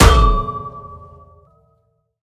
steel_pipe_hit.ogg